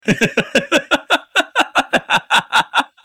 Man Laughing
Man_laughing.mp3